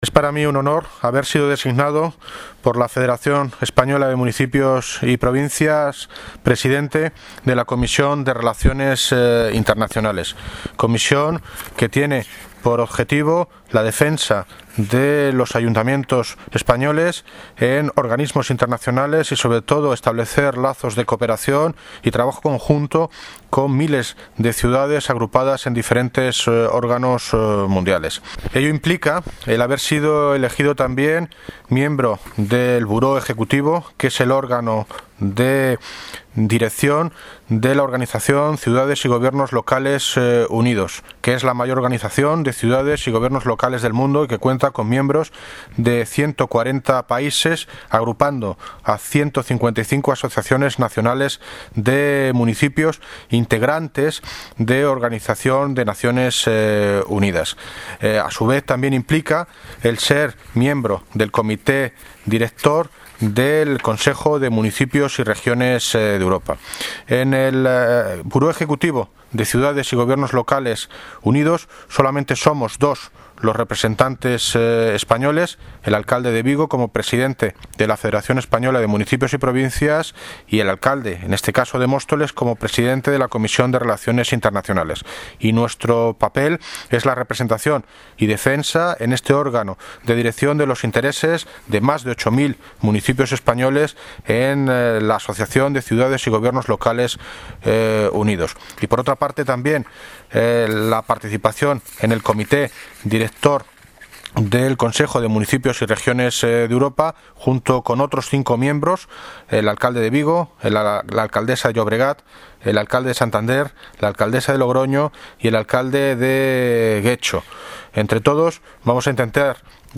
Audio - David Lucas (Alcalde de Móstoles) Sobre Participación en la Cumbre del Clima COP 21